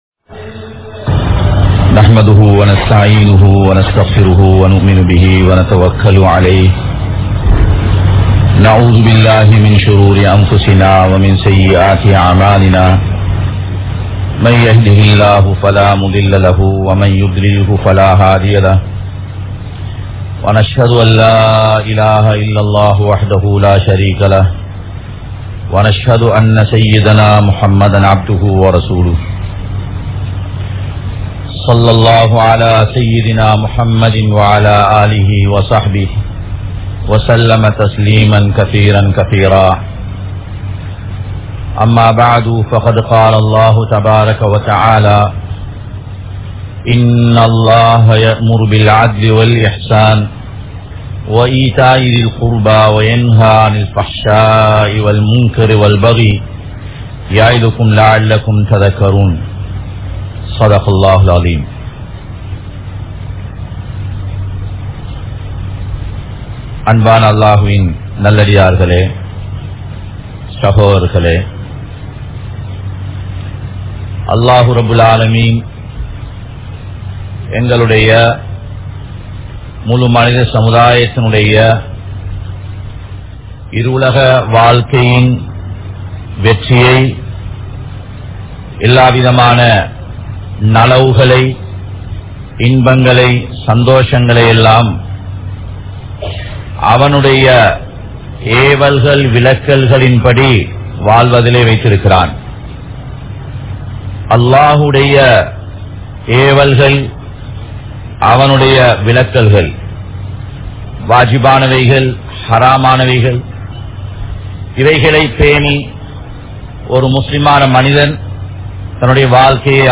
Islam Koorum Ahlaaq (இஸ்லாம் கூறும் அஹ்லாக்) | Audio Bayans | All Ceylon Muslim Youth Community | Addalaichenai